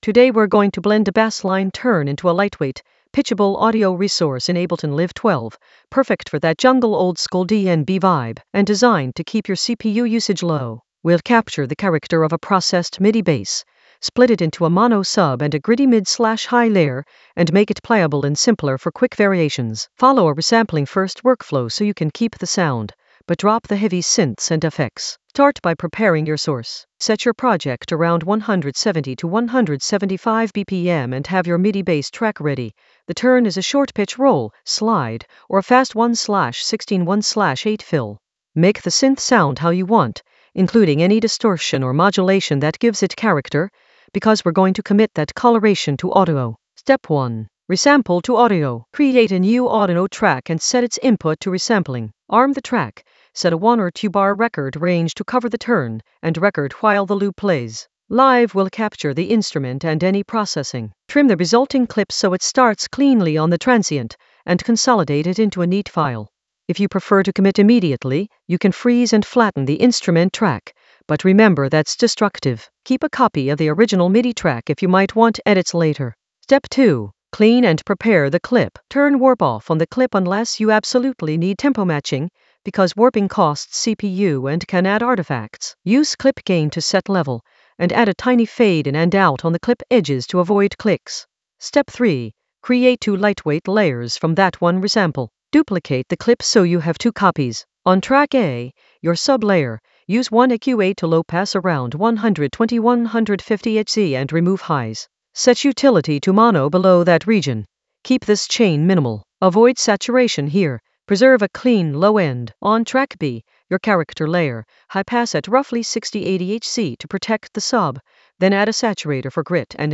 An AI-generated intermediate Ableton lesson focused on Blend a bassline turn with minimal CPU load in Ableton Live 12 for jungle oldskool DnB vibes in the Resampling area of drum and bass production.
Narrated lesson audio
The voice track includes the tutorial plus extra teacher commentary.